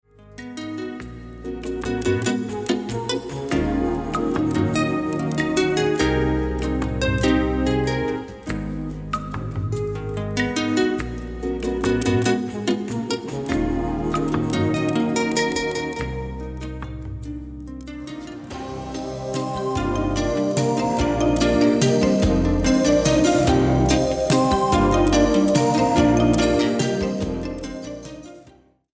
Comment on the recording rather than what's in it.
Re-mastered, includes bonus songs and bonus videos. Recorded spring 1986 at the Sinus Studios, Bern Switzerland